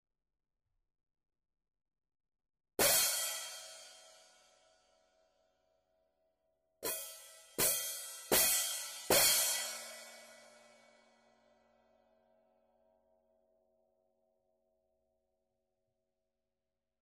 Perfectly matched cymbal pair with a powerful and strong high-end. Quick response with a controlled feel.